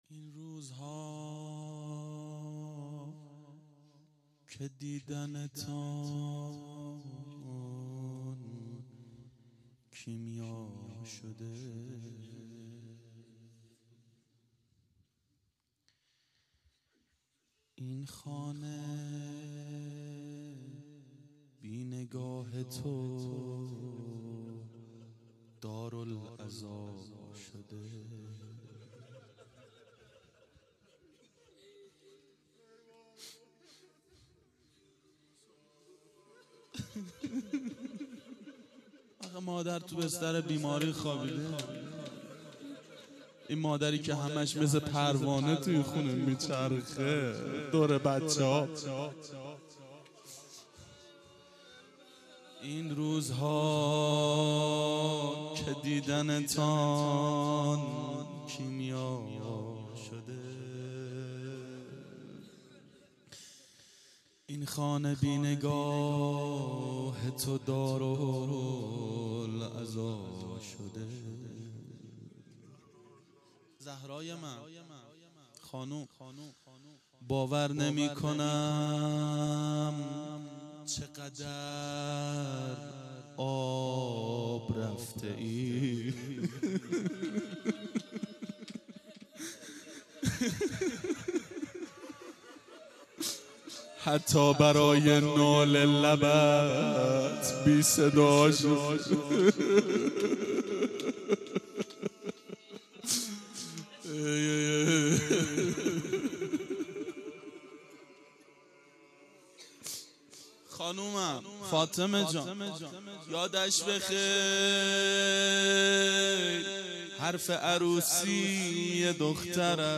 روضه حضرت زهرا سلام الله علیها و امام حسین علیه السلام
شب شهادت حضرت زهرا سلام الله علیها 93
01-روضه-حضرت-زهرا-و-امام-حسین.mp3